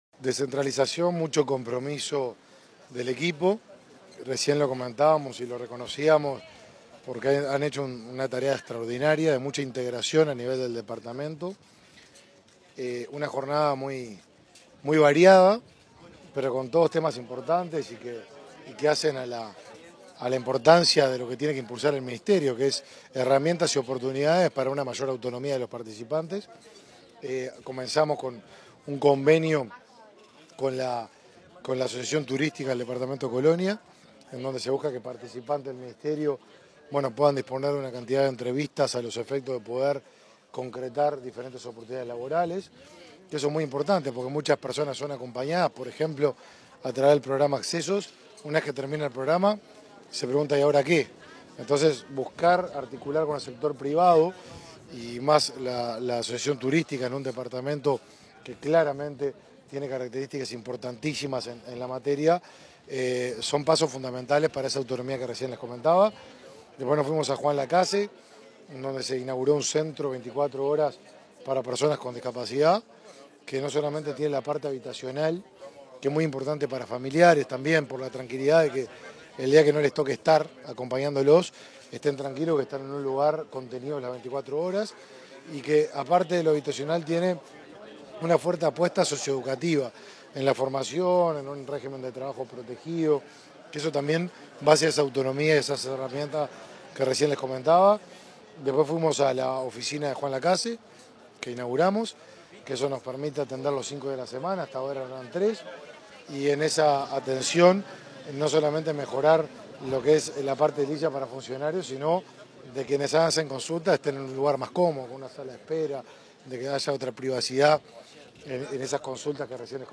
Declaraciones del ministro de Desarrollo Social, Martín Lema, a Comunicación Presidencial
Declaraciones del ministro de Desarrollo Social, Martín Lema, a Comunicación Presidencial 21/12/2022 Compartir Facebook X Copiar enlace WhatsApp LinkedIn Tras varias firmas de convenios e inauguraciones en el departamento de Colonia, este 20 de diciembre, el ministro de Desarrollo Social, Martín Lema, dialogó con Comunicación Presidencial.
entrevista.mp3